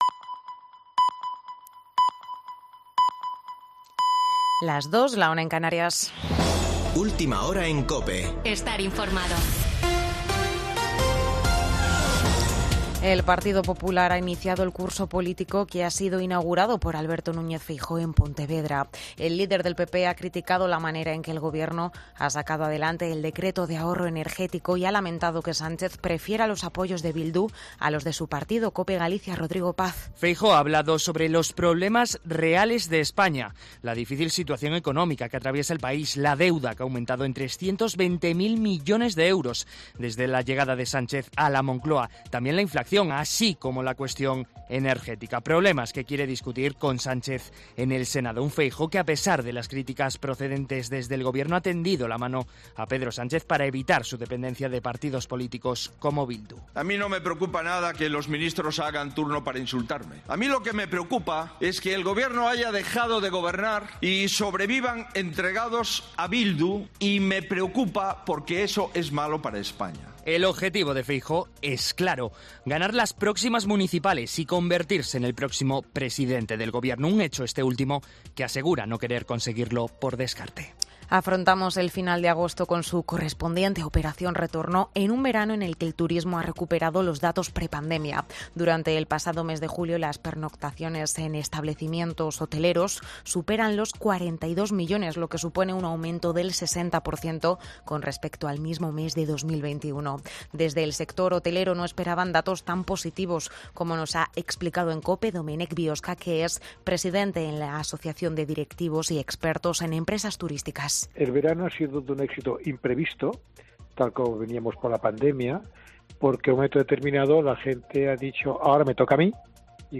Boletín de noticias de COPE del 28 de agosto de 2022 a las 02.00 horas